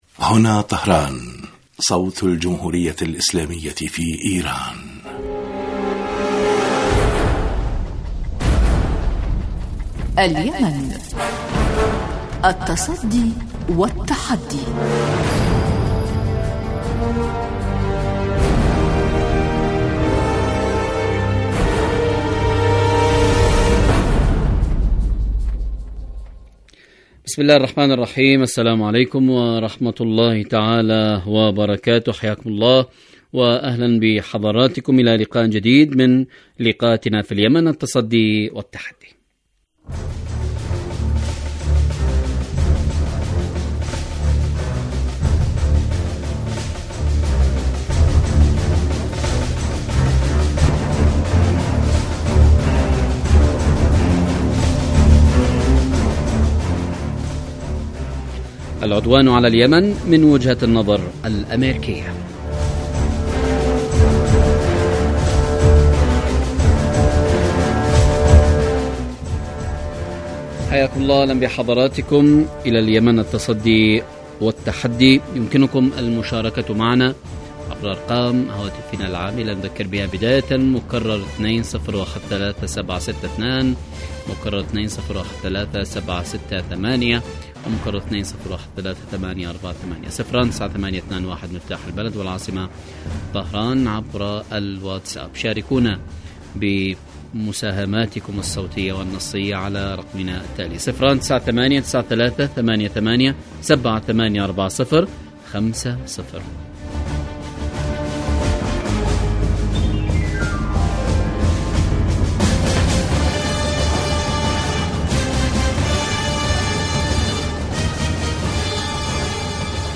إذاعة طهران-اليمن التصدي والتحدي: ضيف الحلقة عبدالسلام الحكيمي السفير في ديوان وزارة الخارجية اليمنية من لندن.
برنامج سياسي حواري يأتيكم مساء كل يوم من إذاعة طهران صوت الجمهورية الإسلامية في ايران
البرنامج يتناول بالدراسة والتحليل آخر مستجدات العدوان السعودي الأمريكي على الشعب اليمني بحضور محللين و باحثين في الاستوديو